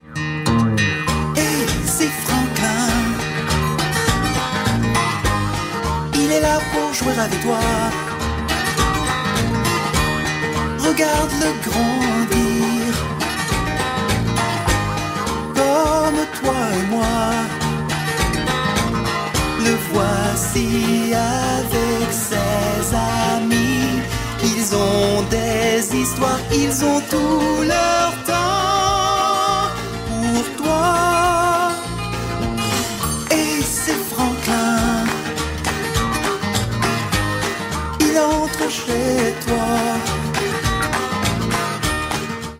Catégorie POP